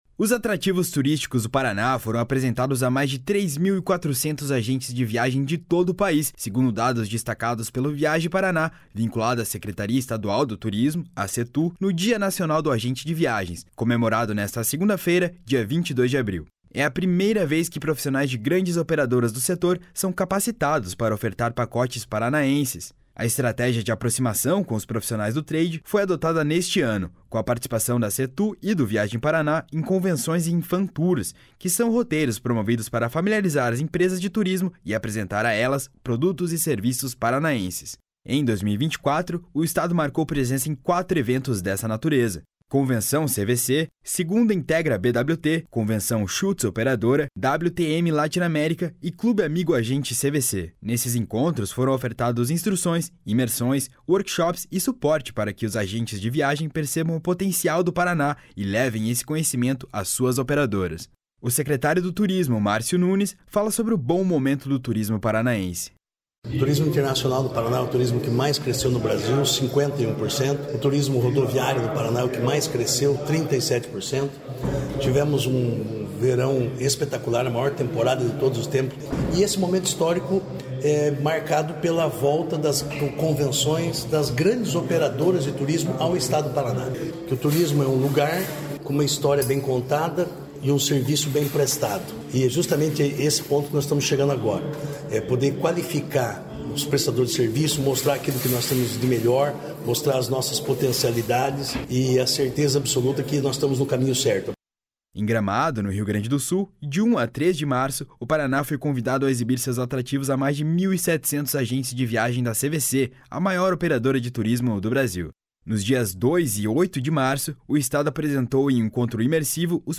O secretário do Turismo, Márcio Nunes, fala sobre o bom momento do turismo paranaense.